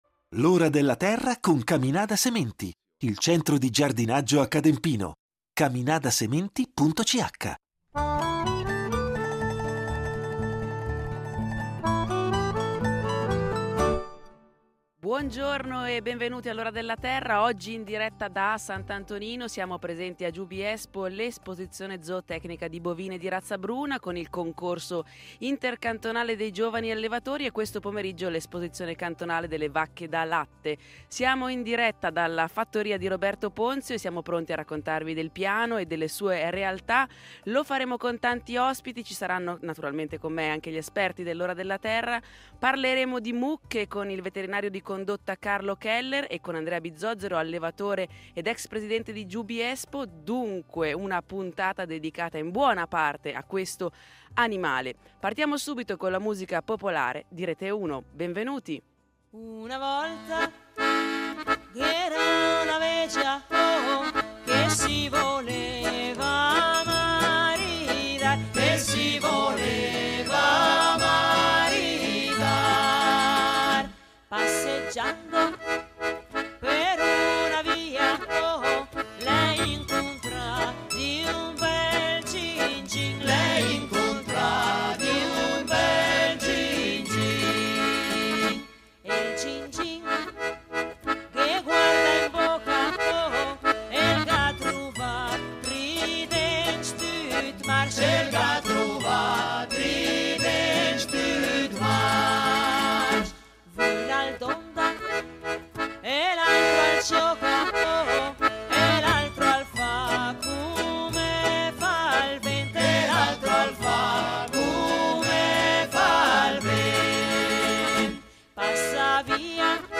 In diretta da Giubiespo , a S.Antonino, presso la Fattoria Ponzio (e in caso di cattivo tempo al mercato coperto di Giubiasco)